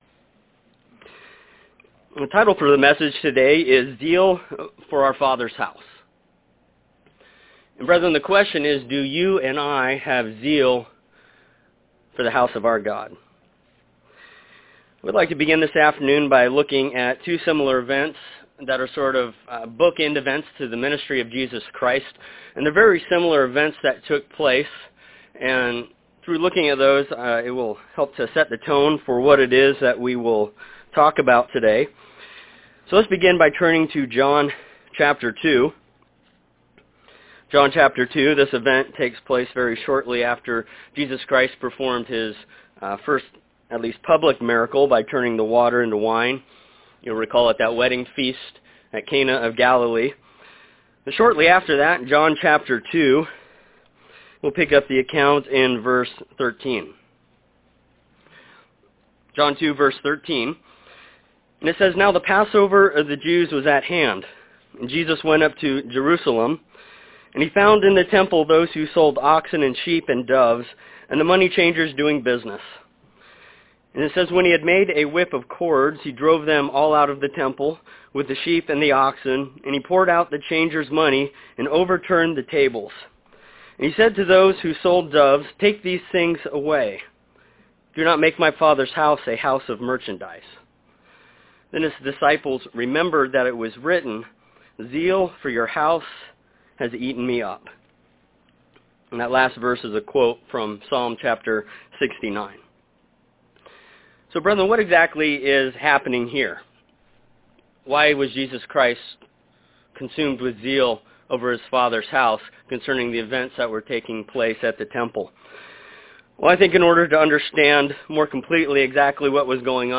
Sermon on Saturday, November 21, 2015 in Kennewick, Washington During Jesus' ministry He displayed zeal for His Father's house by cleansing the temple.